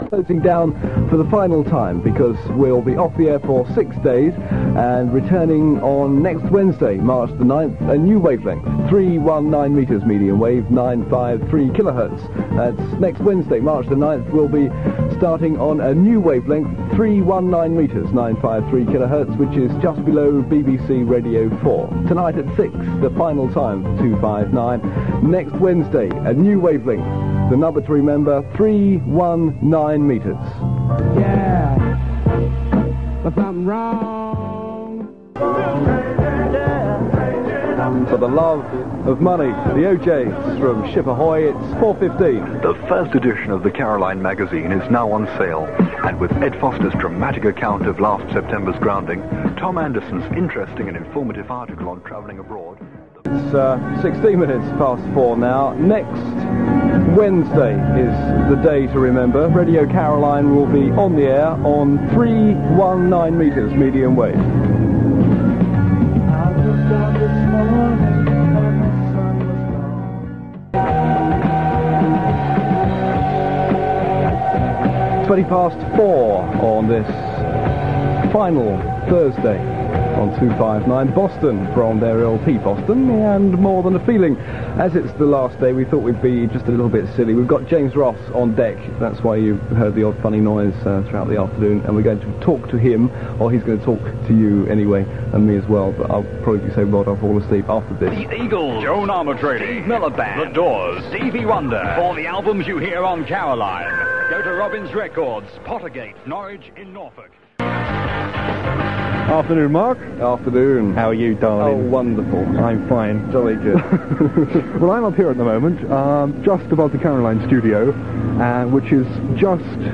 click to hear audio Some extracts from Radio Caroline's last show on 259 metres, 3rd March 1977.
out and about on the ship